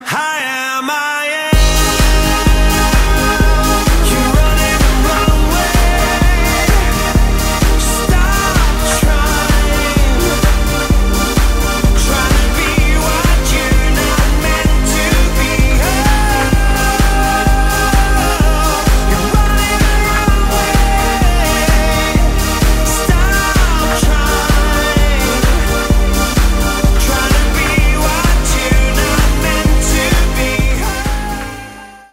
• Качество: 320, Stereo
мужской голос
громкие
Electronic
Trance
Стиль: trance